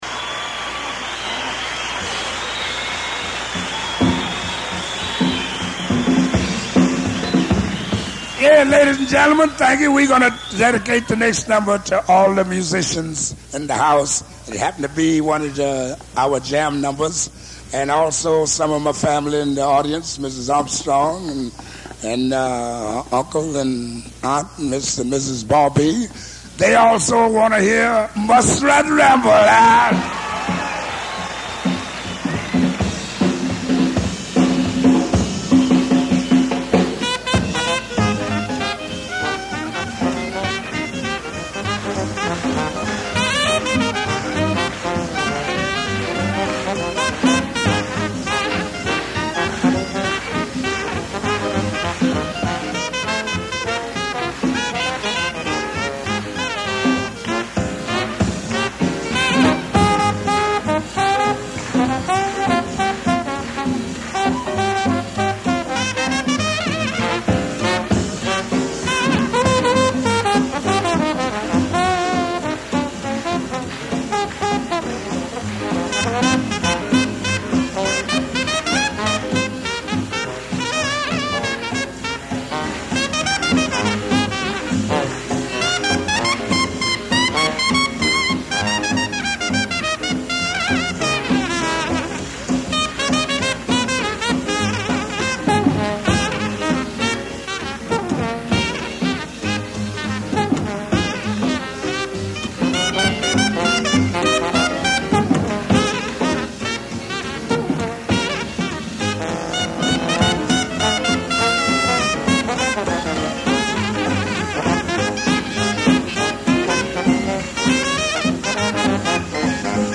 Hinsdale High School, March 20, 1957